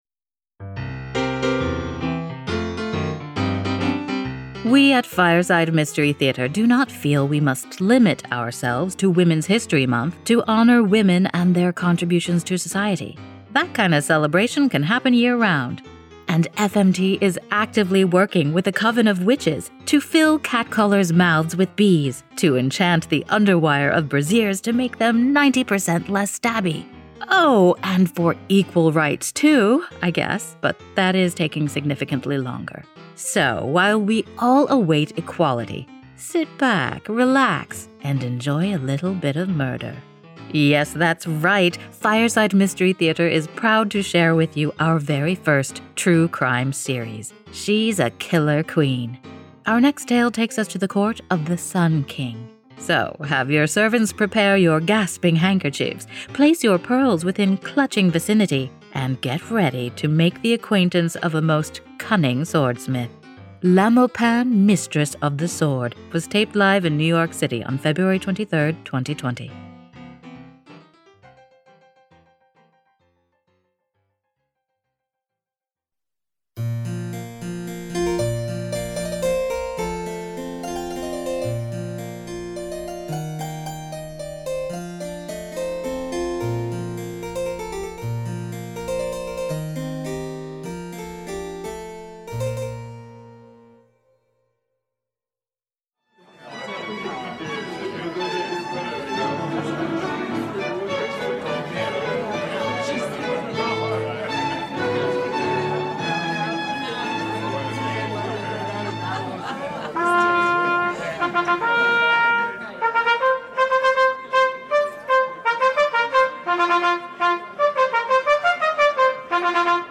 She's a Killer Queen was recorded live in NYC on 2/23/20.
Audio play
Musical score improvised